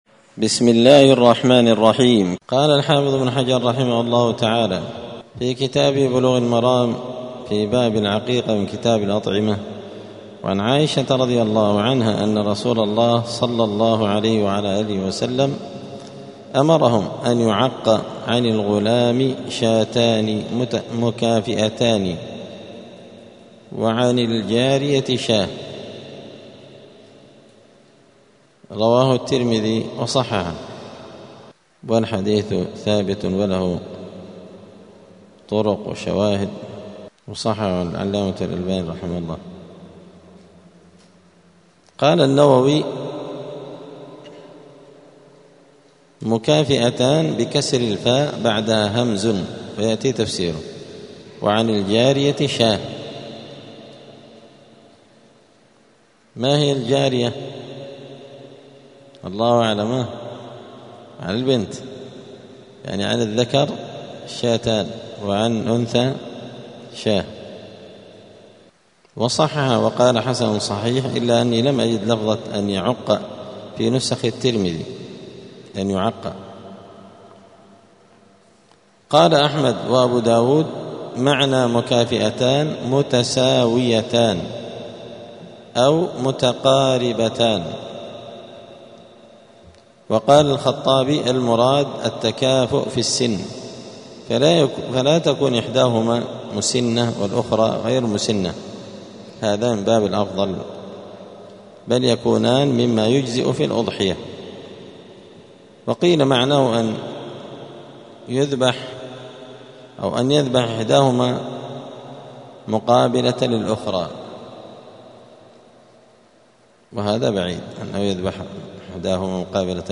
*الدرس التاسع والعشرون (29) {باب العقيقة}*